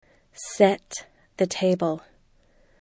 set (the table)  sɛt